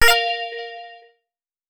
Buttons Colletions Demo
synthe_15.wav